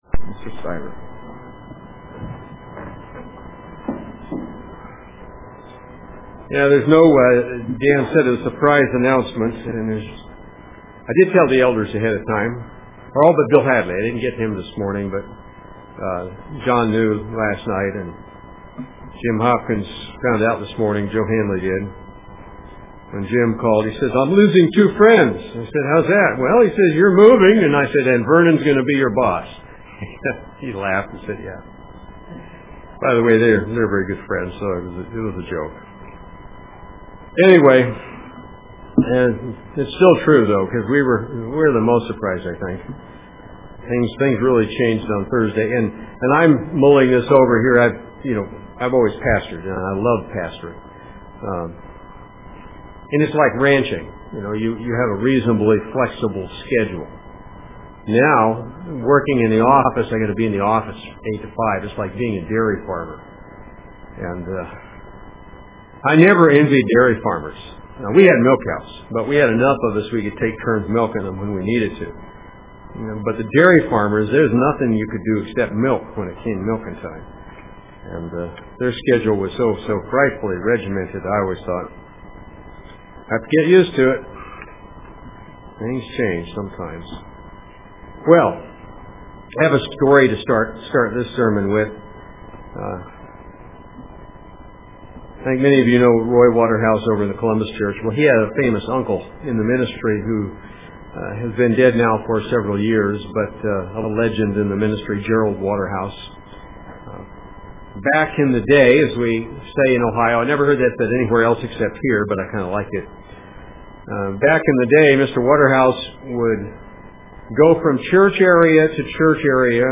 UCG Sermon